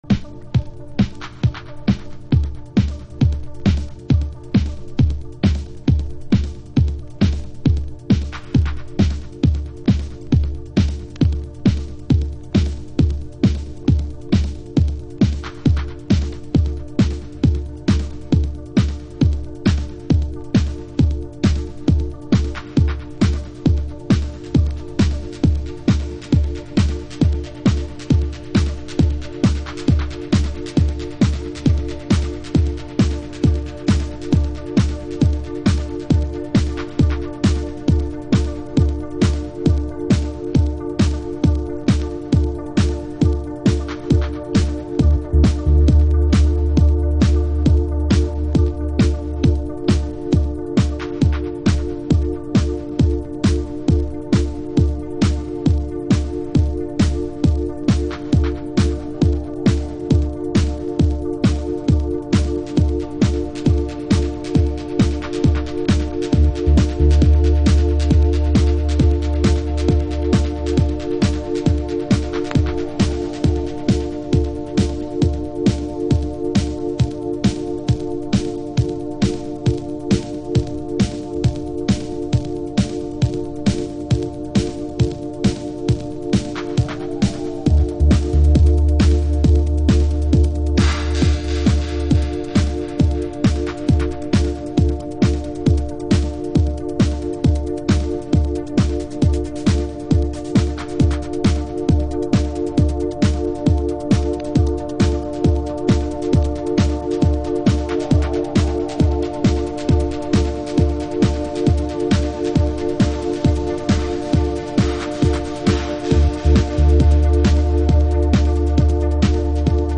House / Techno
マイクロトランスがディスコに浸食されたようなトラックは独創的なグルーヴを刻んでおります。